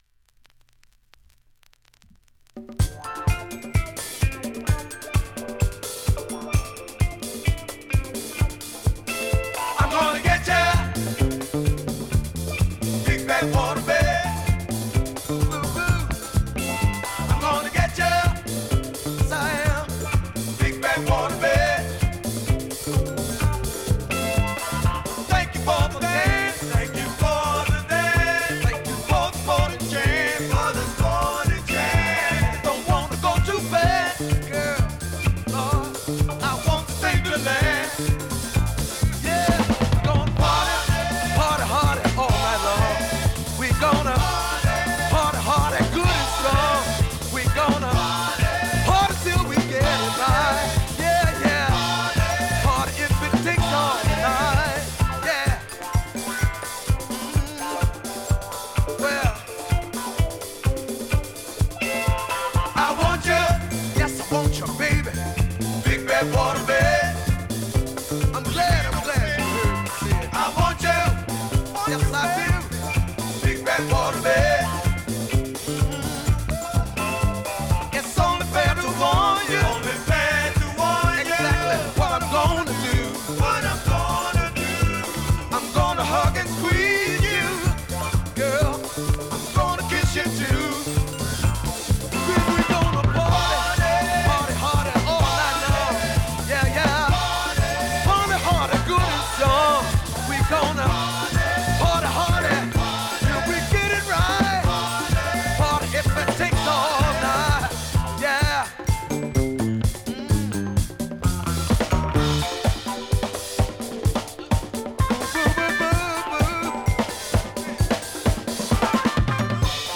現物の試聴（両面すべて録音時間７分４１秒）できます。
(Instrumental)